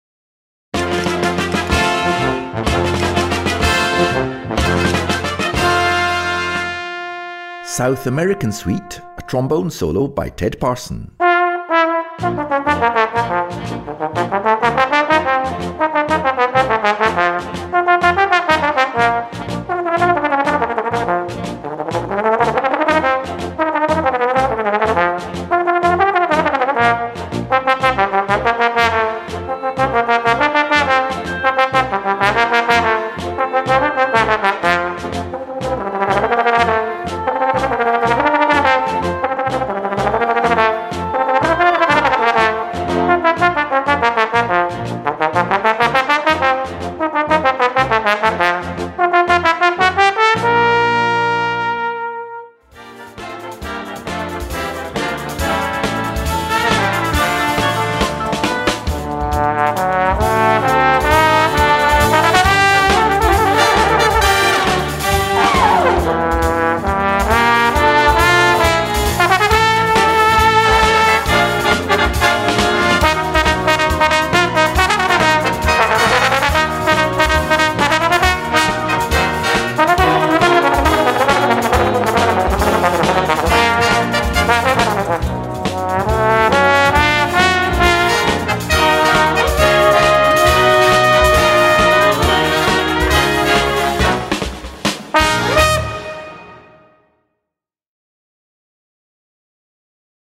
Gattung: Solo für Posaune und Blasorchester
Besetzung: Blasorchester